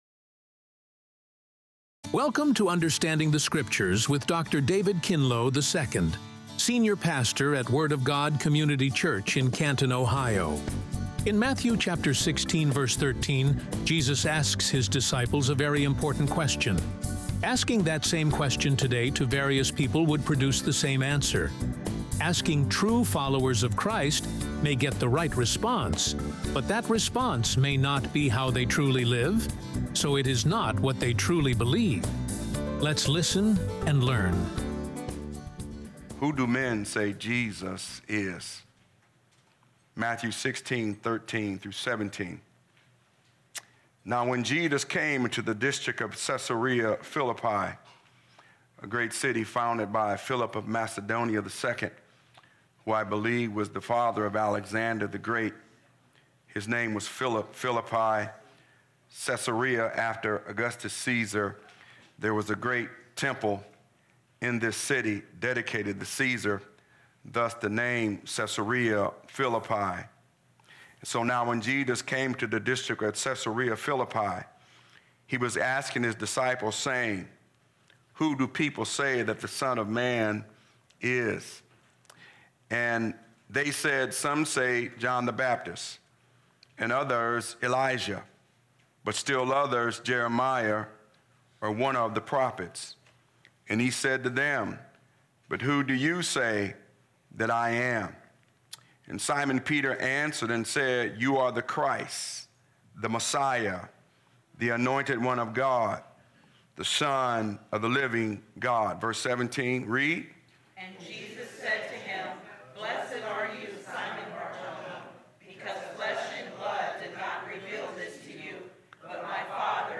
Radio Sermons | The Word of God Community Church